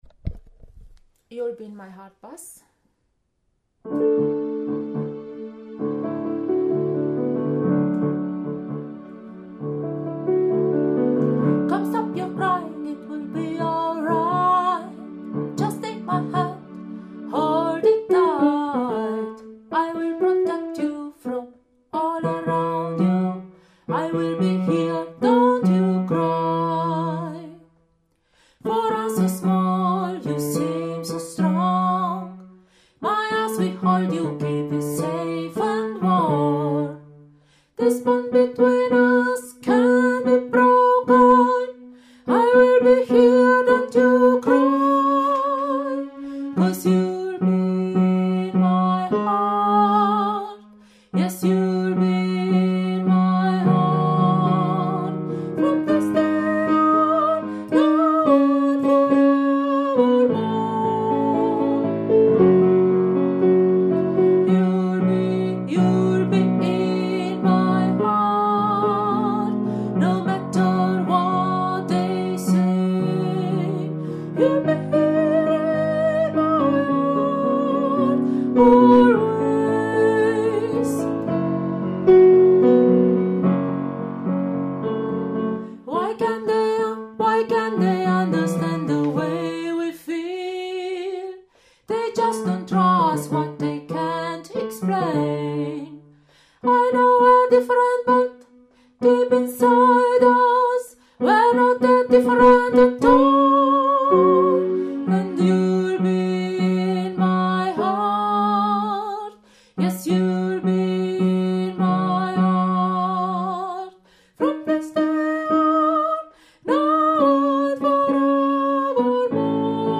Youll-be-in-my-heart-Bass.mp3